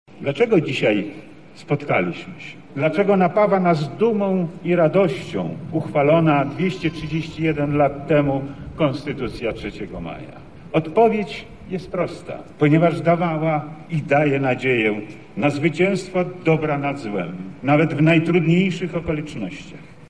Główne obchody w Lublinie odbyły się na placu Litewskim.
Podczas obchodów głos zabrał wojewoda lubelski Lech Sprawka: